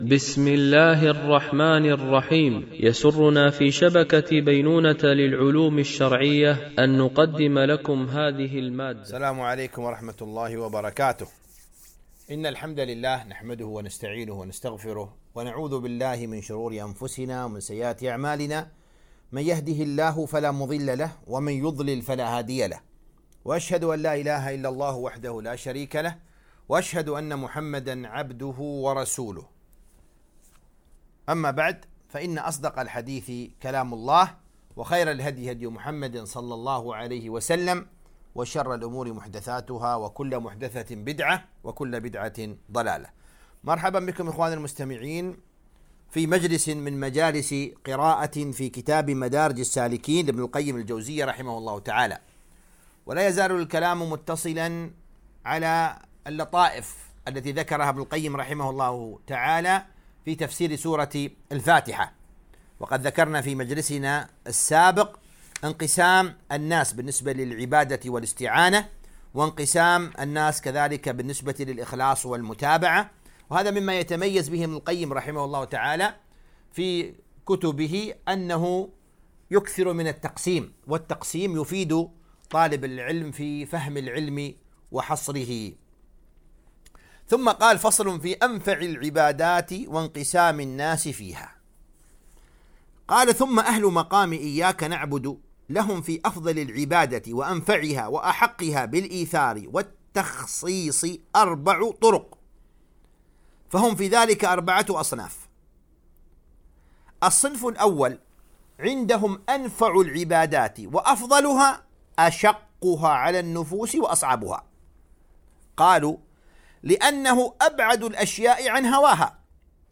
قراءة من كتاب مدارج السالكين - الدرس 12
MP3 Mono 44kHz 96Kbps (VBR)